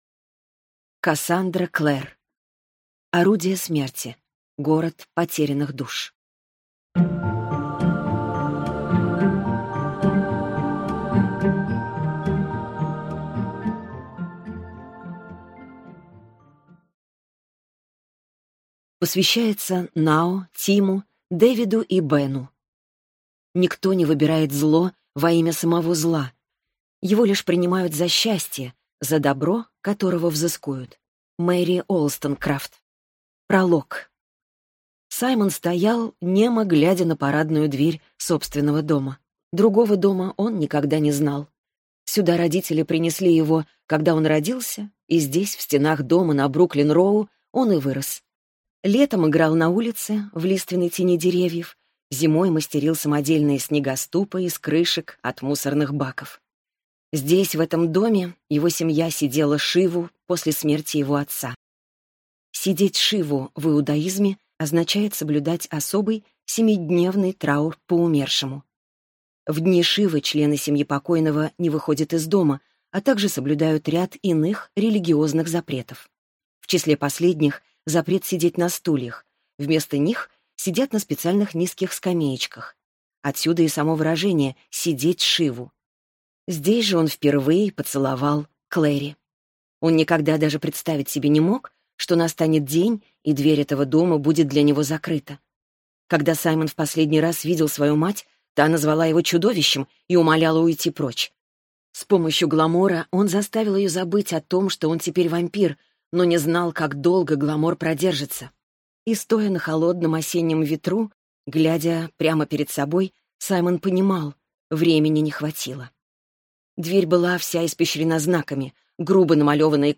Аудиокнига Орудия смерти. Город потерянных душ | Библиотека аудиокниг